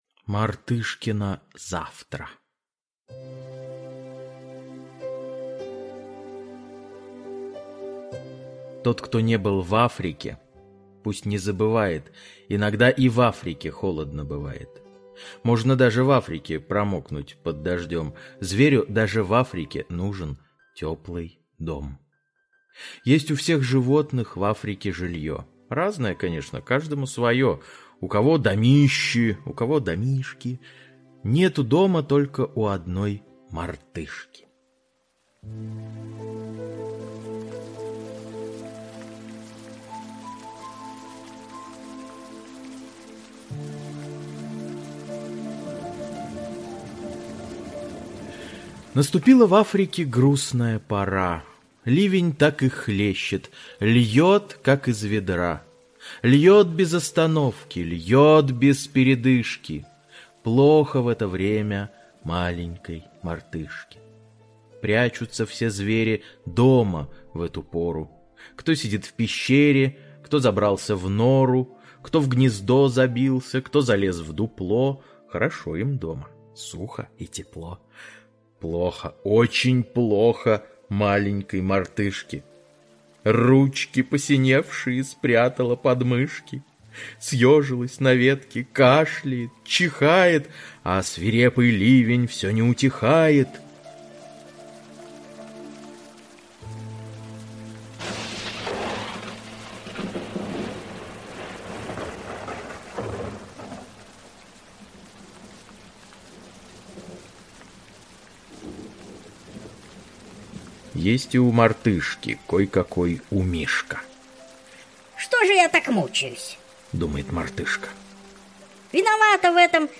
Мартышкино завтра - аудиосказка Заходера - слушать онлайн